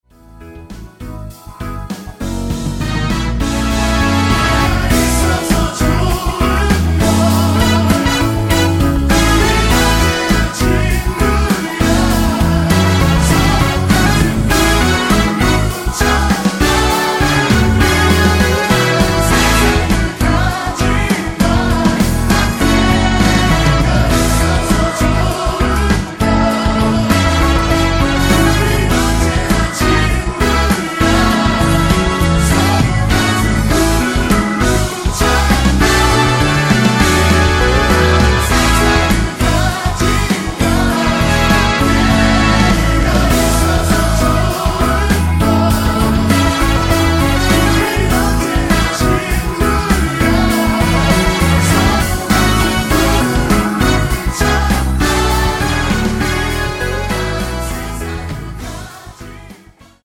원곡 3분 지나서 부터 나오는 코러스 포함된 MR입니다.(미리듣기 확인)
원키에서(-1)내린 코러스 포함된 MR입니다.
앞부분30초, 뒷부분30초씩 편집해서 올려 드리고 있습니다.
곡명 옆 (-1)은 반음 내림, (+1)은 반음 올림 입니다.